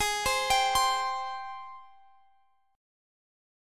AbM7 Chord
Listen to AbM7 strummed